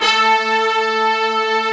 Index of /90_sSampleCDs/AKAI S-Series CD-ROM Sound Library VOL-1/BRASS SECT#1